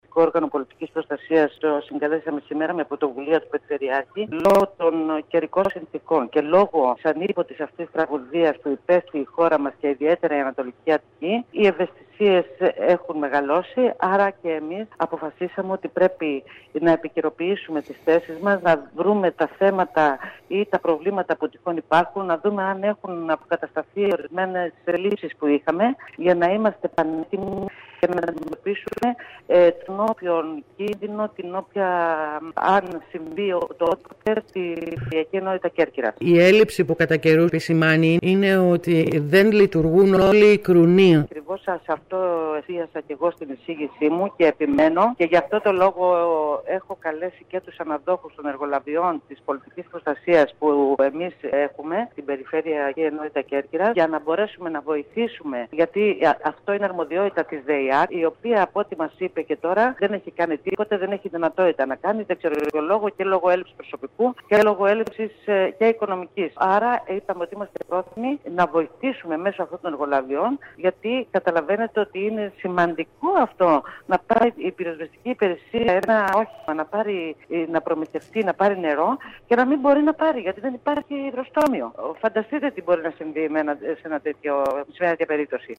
Ακούμε την Αντιπεριφερειάρχη Νικολέτα Πανδή